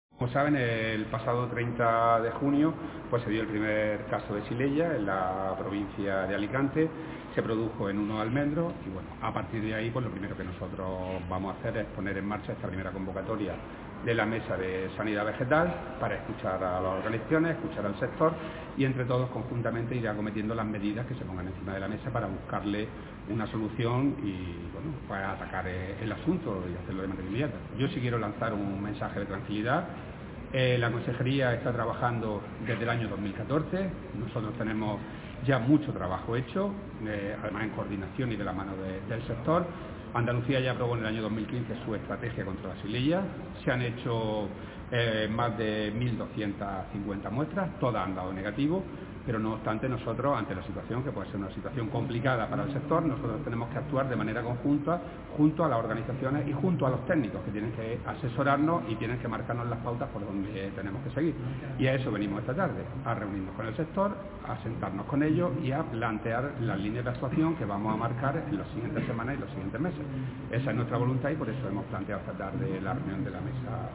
Declaraciones consejero Xylella